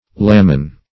(-n[=e]) E. Laminas (-n[.a]z).